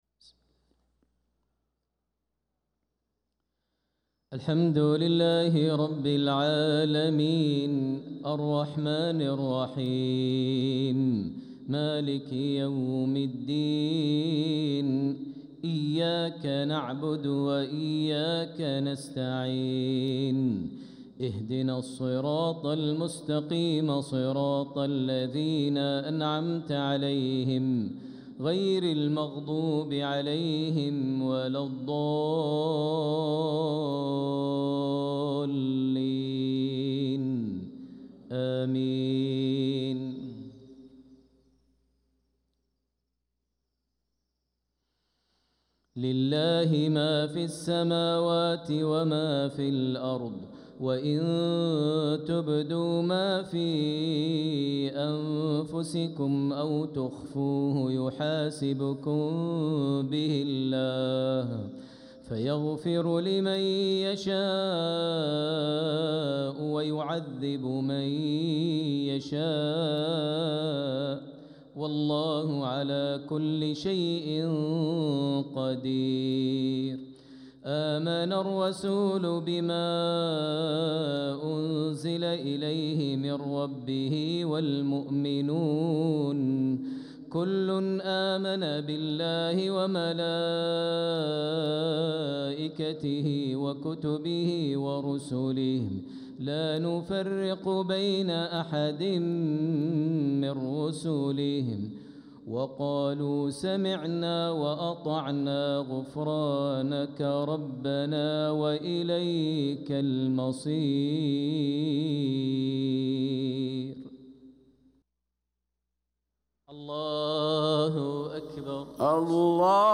صلاة المغرب للقارئ ماهر المعيقلي 20 صفر 1446 هـ